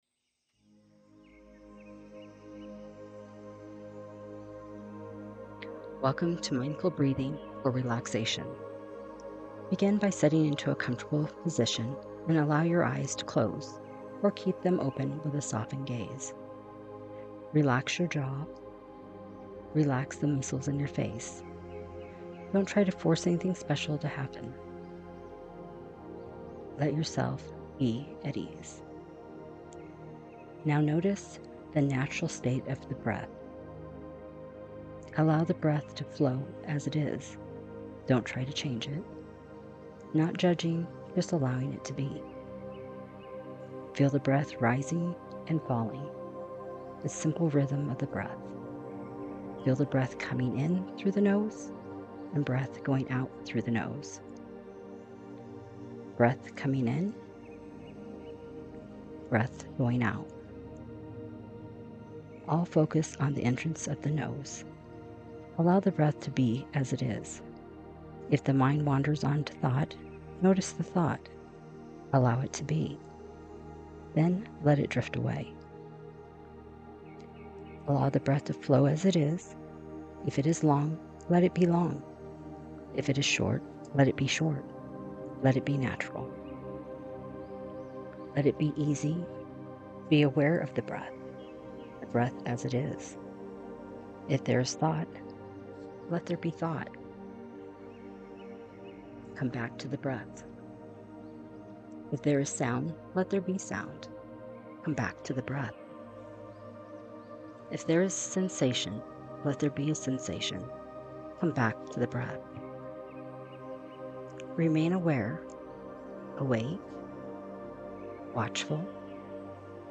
Guided Meditation
This calming guided meditation and mindful breathing exercise is designed to reduce stress, improve focus, and bring you back to center.
music-Mindfulness-Breathing-for-Relaxation-1.mp3